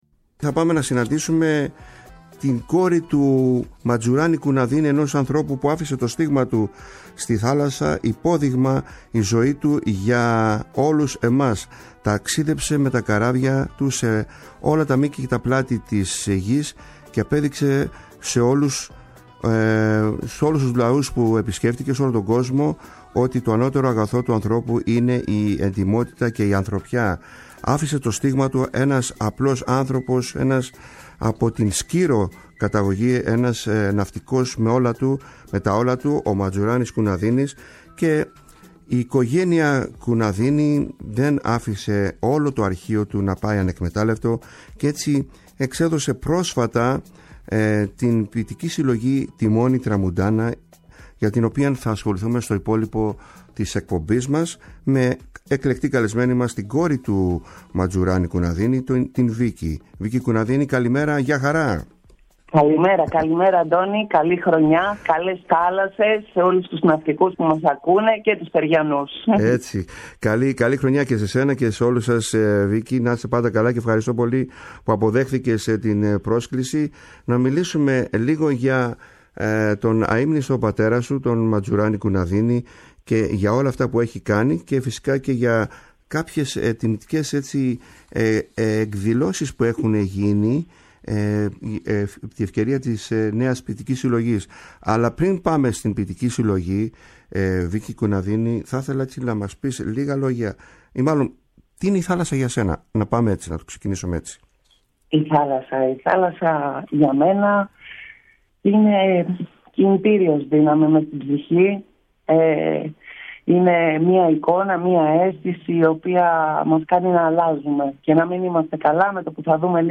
Ξεχώρισε και μας διάβασε κάποια από τα ξεχωριστά αυτά ναυτικά ποιήματα και πολλά άλλα.
Η ΦΩΝΗ ΤΗΣ ΕΛΛΑΔΑΣ Καλες Θαλασσες ΣΥΝΕΝΤΕΥΞΕΙΣ Συνεντεύξεις